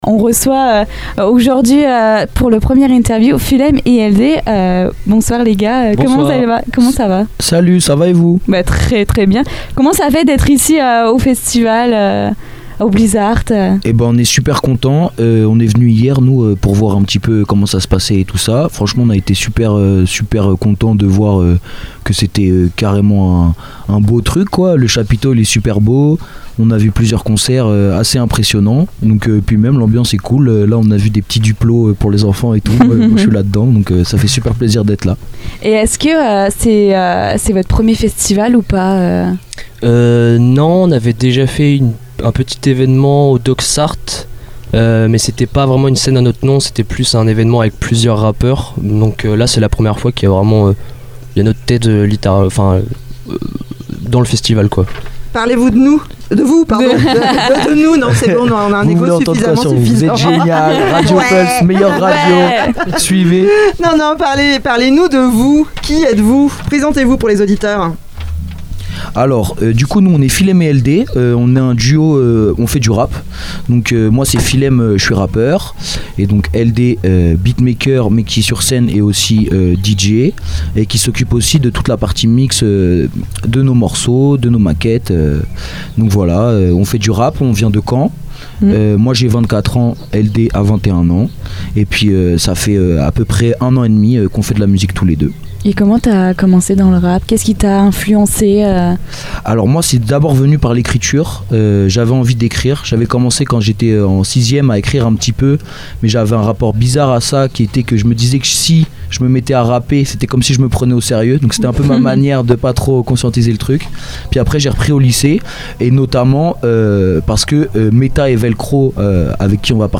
À cette occasion, nous les avons retrouvés dans la Mystery Machine (studio radio aménagé dans une camionnette) pour une interview exclusive, diffusée en direct sur Radio Pulse et Radio Coup de Foudre.